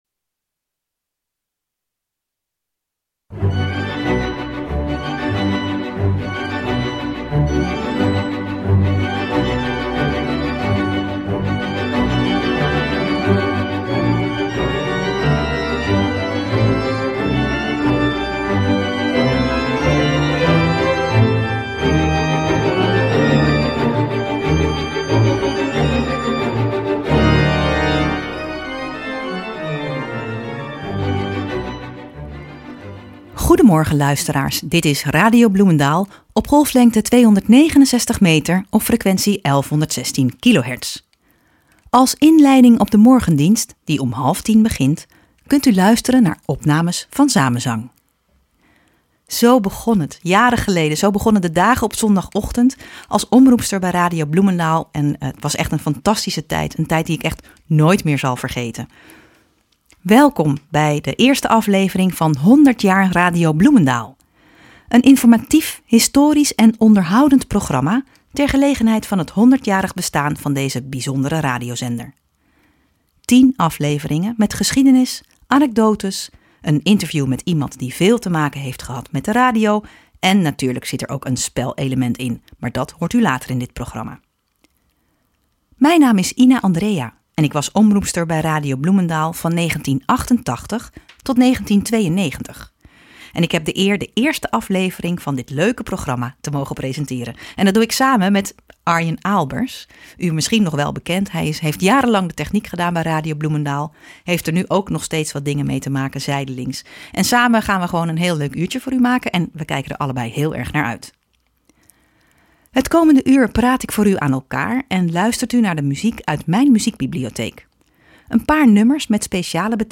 wordt geïnterviewd